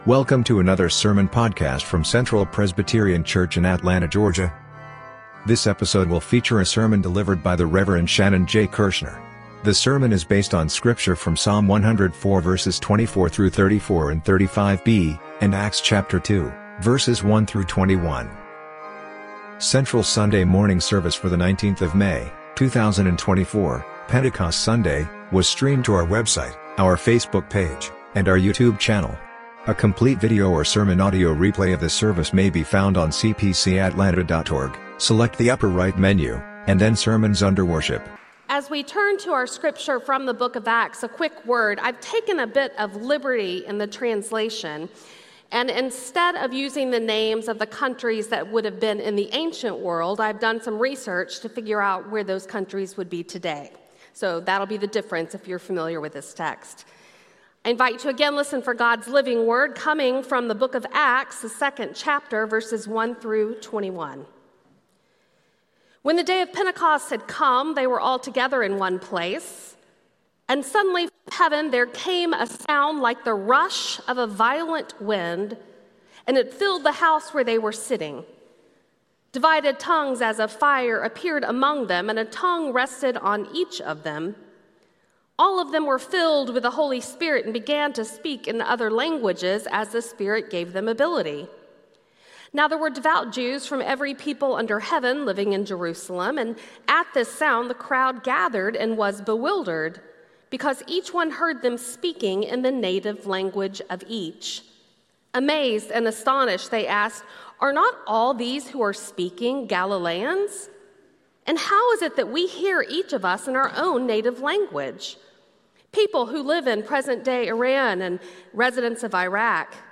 Sermon Audio:
Service Type: Sunday Sermon